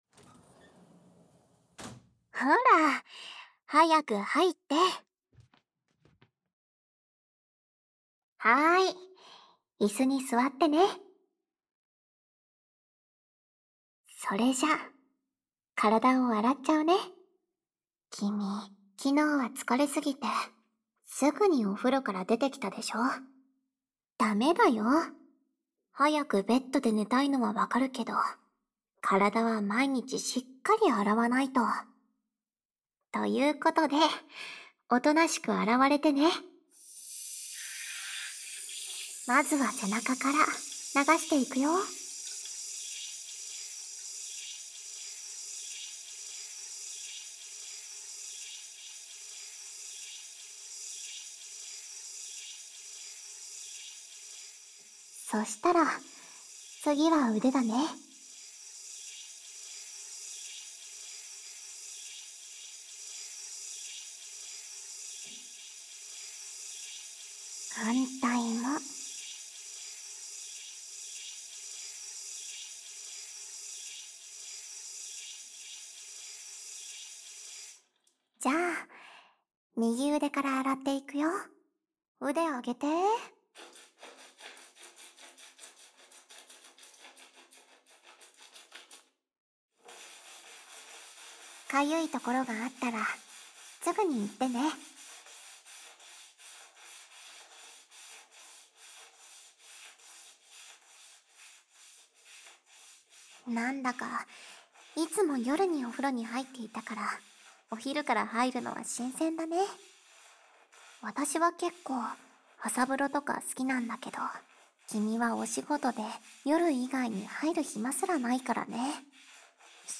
纯爱/甜蜜 温馨 治愈 掏耳 环绕音 ASMR 低语
el97_07_『洗个澡清爽下吧』（冲背）.mp3